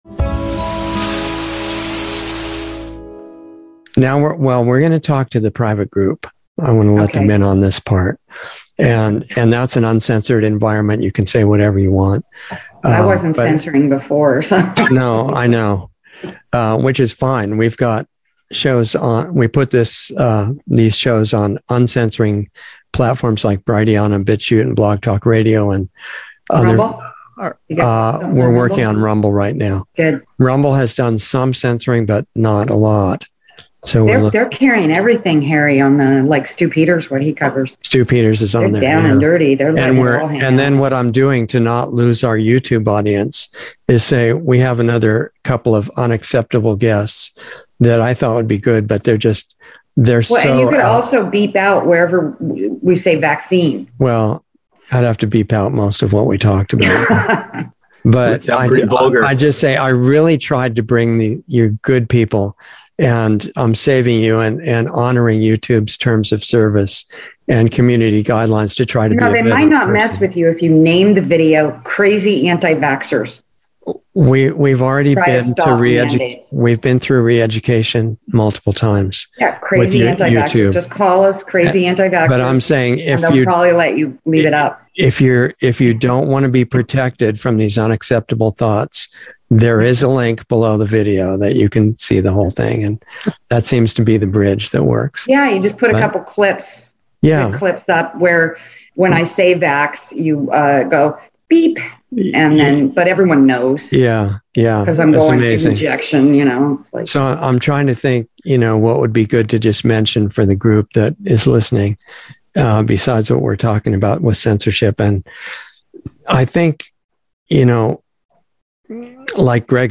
Insider Interview 3/25/22 0:25:00 הירשם 19 lostartsradio 3 שנים 9 צפיות תרומה אנא התחברו לתרומה MP3 להוסיף ל רוצה לראות את זה שוב מאוחר יותר?